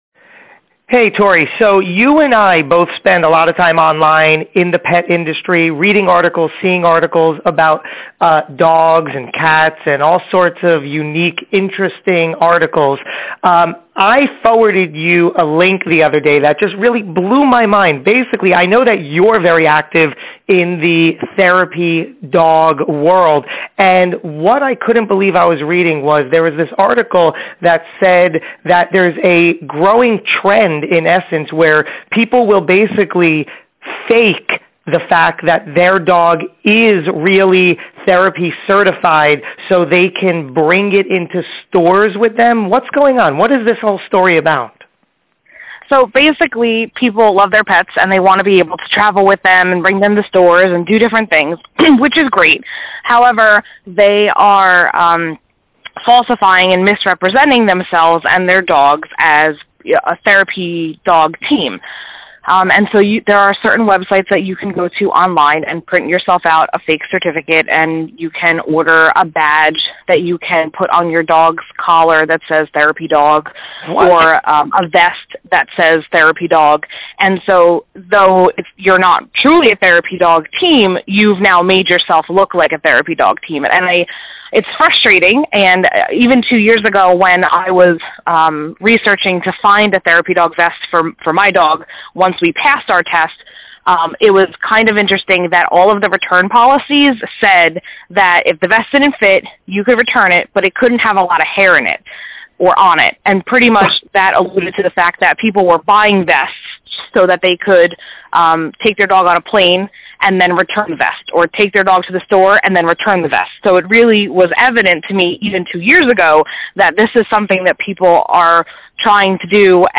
Here is a quick audio clip of another pet care professional and I talking about this subject.